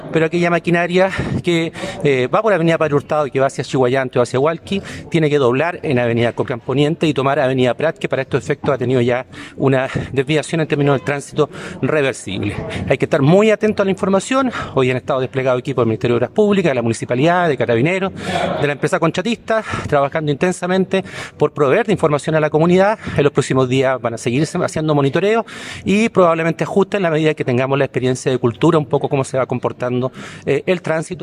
El seremi de Obras Públicas, Hugo Cautivo, señaló que “(…) hay que estar muy atentos a la información. Hoy han estado desplegados equipos del Ministerio de Obras Públicas, de la municipalidad, de Carabineros, de la empresa contratista, trabajando intensamente por proveer de información a la comunidad”.